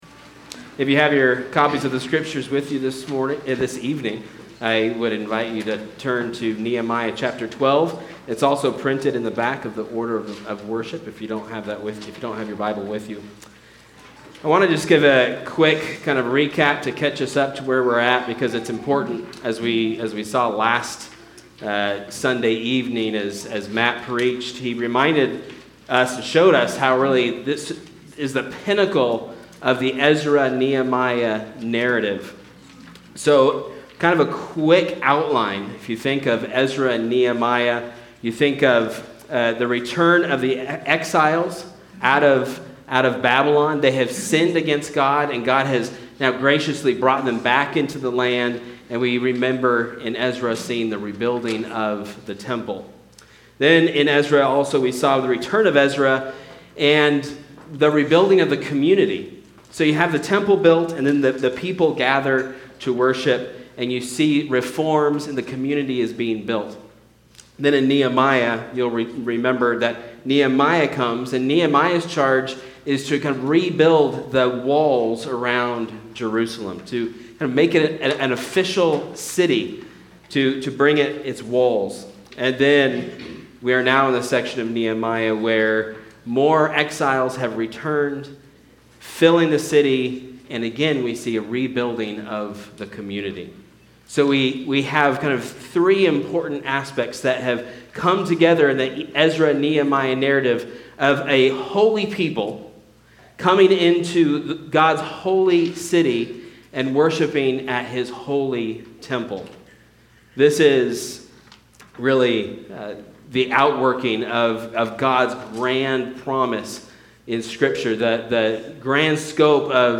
Make A Joyful Noise (Nehemiah 12:27-43) Sermons And Lessons From All Saints Presbyterian Church podcast